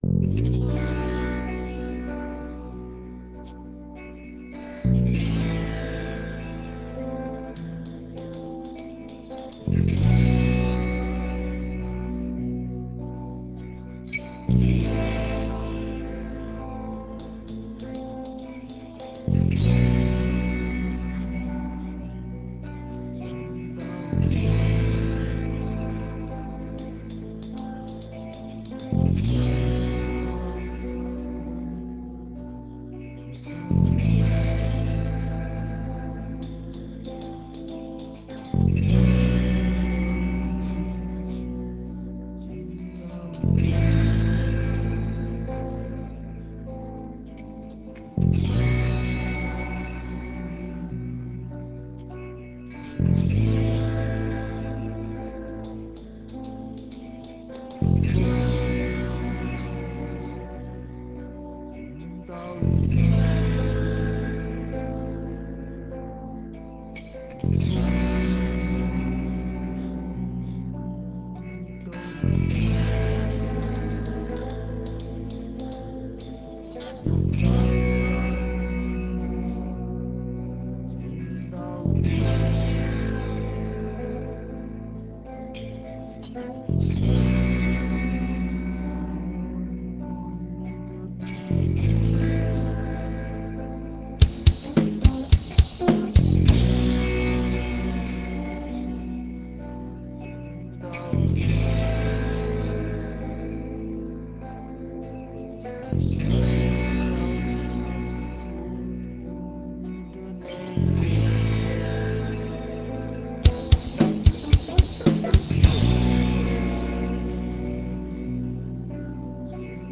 La musique hard taiwanaise (oui oui, ça existe)
et là tout se déchaîne.
(pas de très bonne qualité, mais bien compressé)